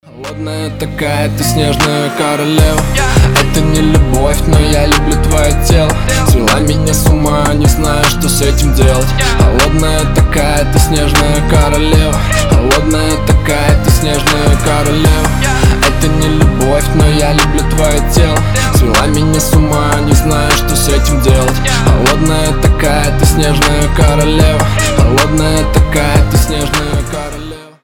мужской вокал
басы